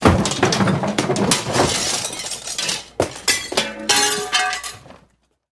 Звуки шкафа
Грохот падающего шкафа со всем содержимым